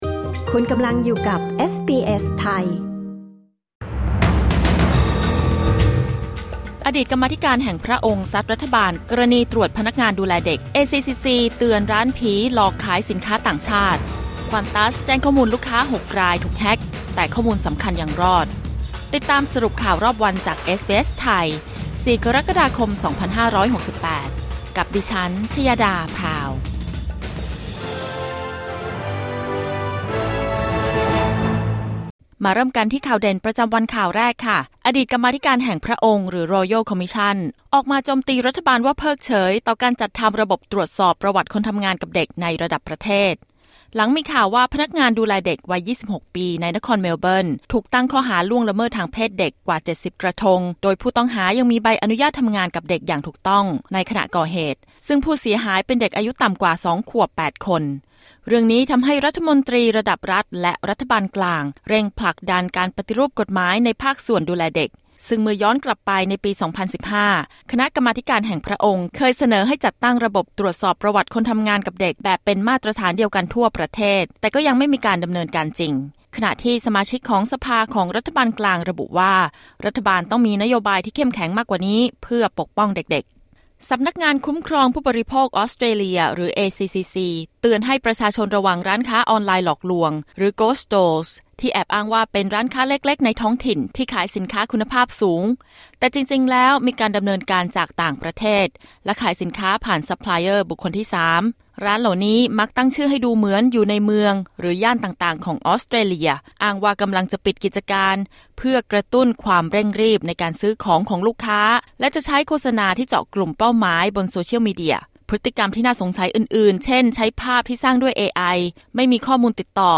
สรุปข่าวรอบวัน 04 กรกฎาคม 2568